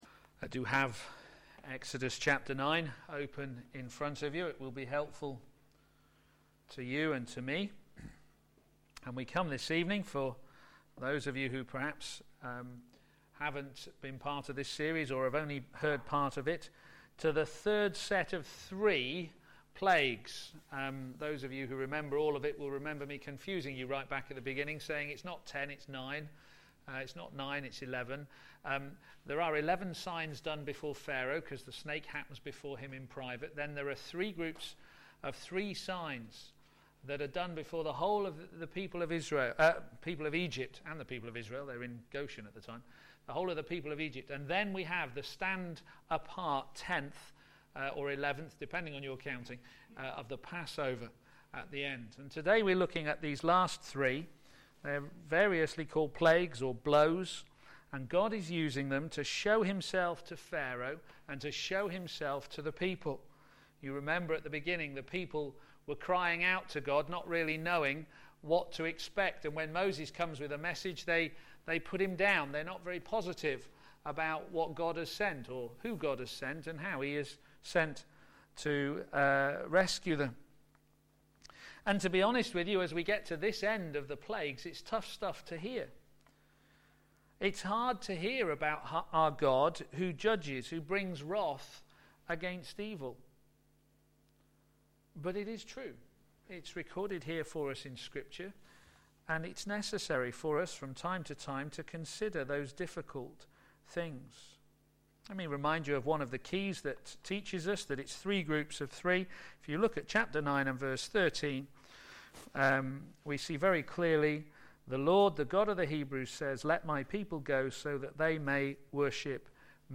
Theme: Knowing the name Sermon